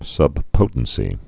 (sŭb-pōtn-sē)